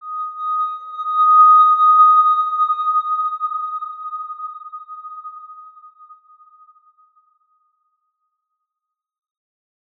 X_Windwistle-D#5-pp.wav